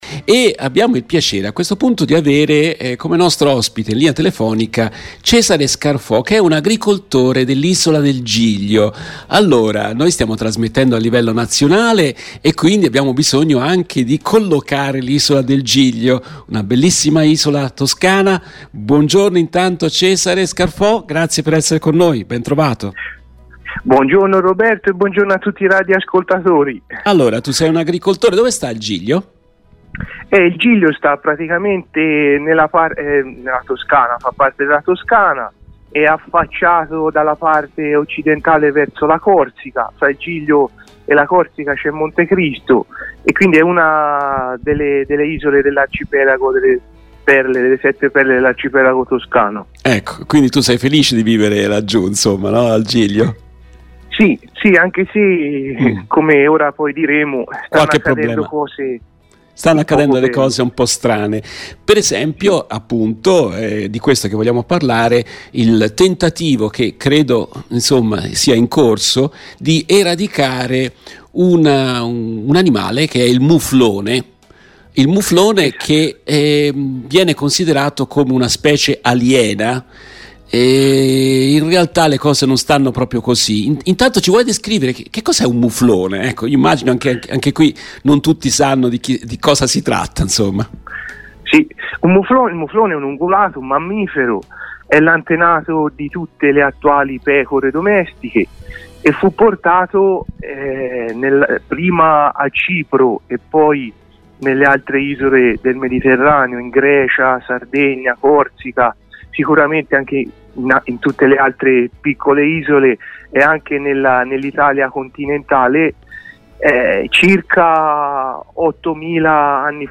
Nel corso della trasmissione in diretta dell'1 novembre 2022